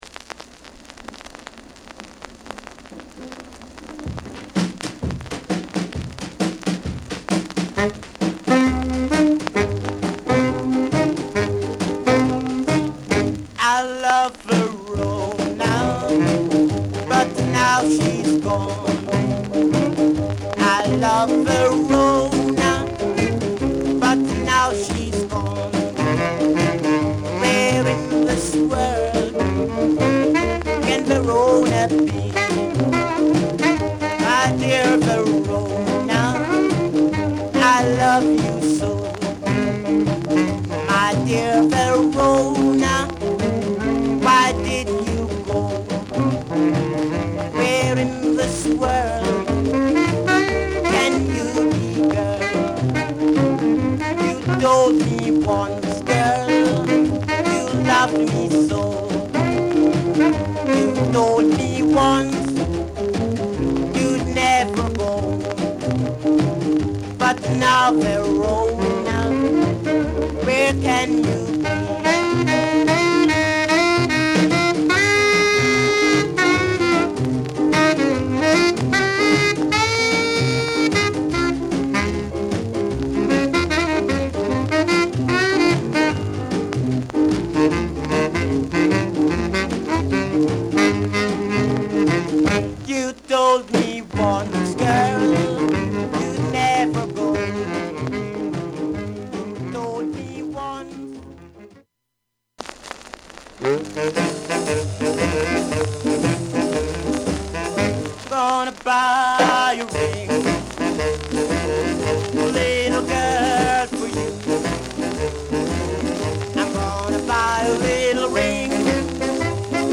Genre: Ska